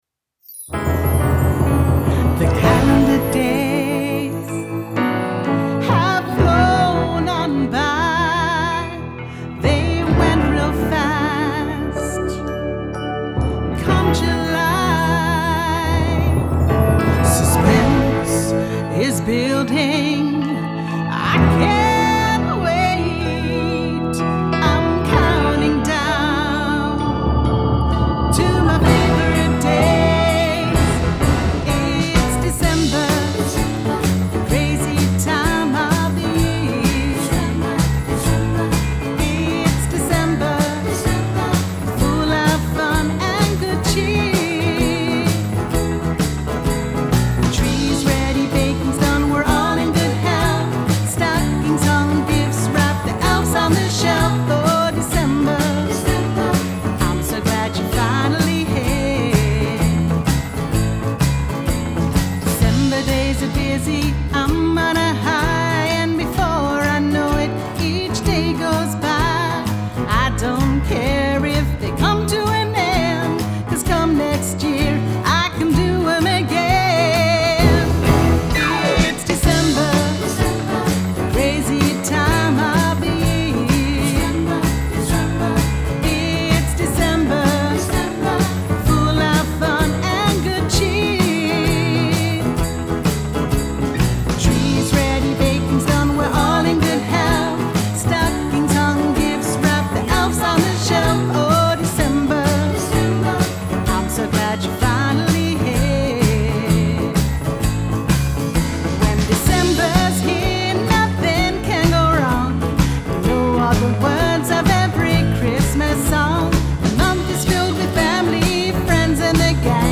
with singalong lyrics